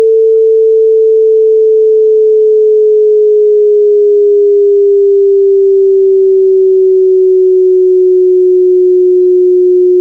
Die Tonbeispiele geben die Tonhöhen, die ein ruhender Beobachter hört, wenn eine Signalquelle an ihm vorbeifliegt.
Frequenz f0 = 400 Hz, relative Geschwindigkeit v/c = 0,1 (dann ist fzu_max = 440 Hz und fweg_min = 360 Hz):
Doppler-Beispiel 3: wie (2), Abstand noch größer.
Doppler-longdist-slow.ogg.mp3